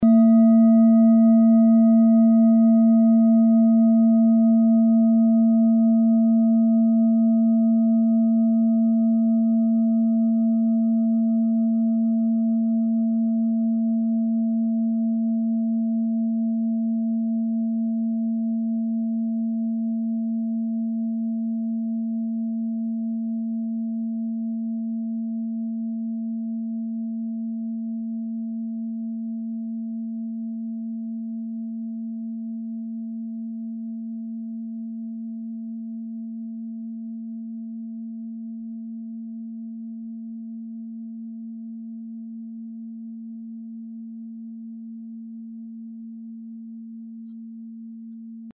Diese Klangschale ist eine Handarbeit aus Bengalen. Sie ist neu und wurde gezielt nach altem 7-Metalle-Rezept in Handarbeit gezogen und gehämmert.
Klangschale Bengalen Nr.3
Hörprobe der Klangschale
Filzklöppel oder Gummikernschlegel